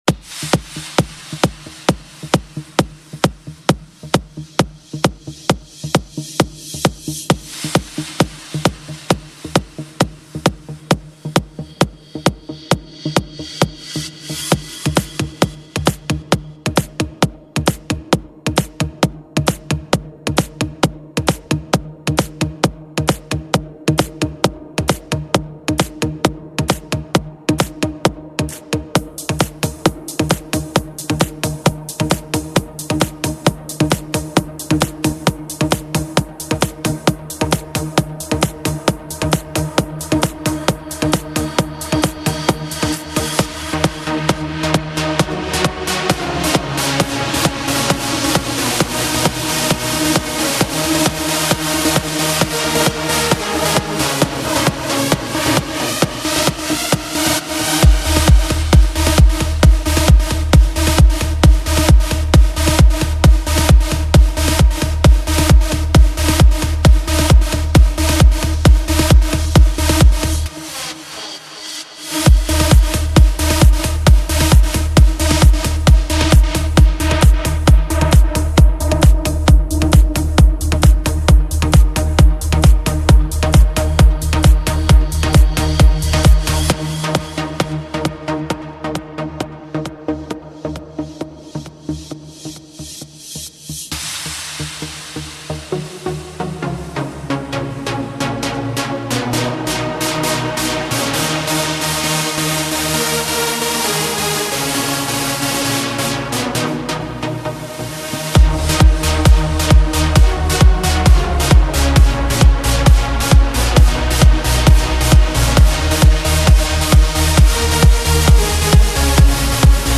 Tribal-Tech-Trance